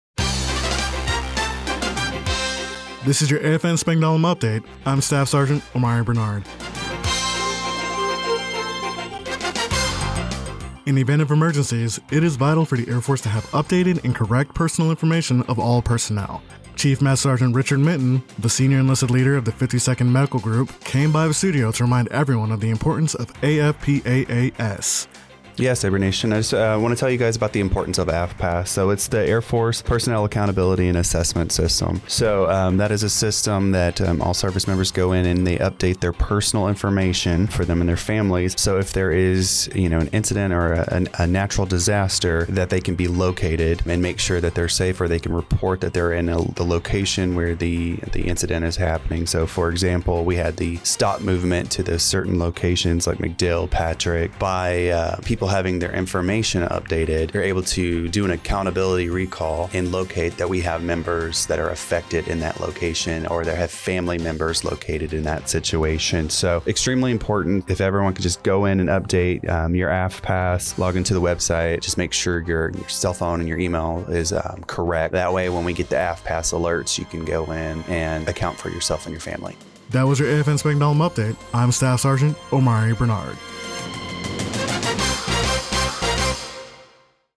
The following was the radio news report for AFN Spangdahlem for Oct. 24, 2024.